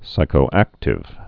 (sīkō-ăktĭv)